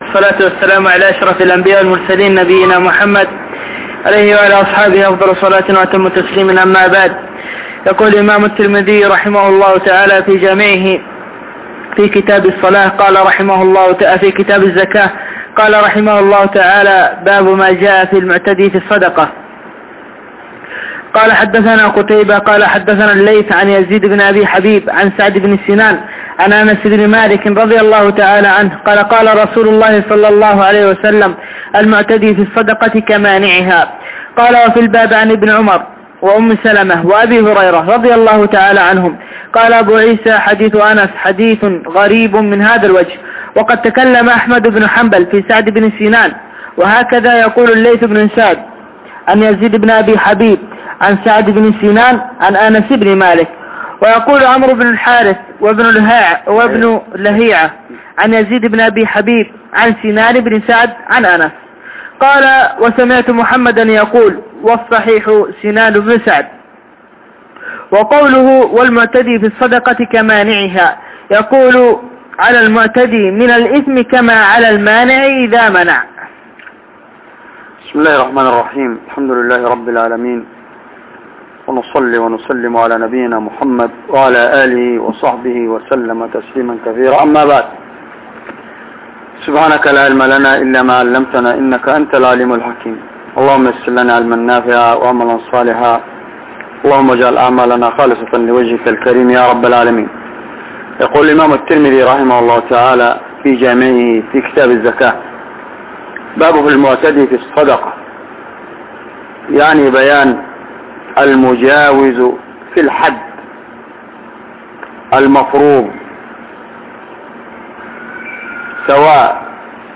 سلسلة دروس شرح جامع الترمذي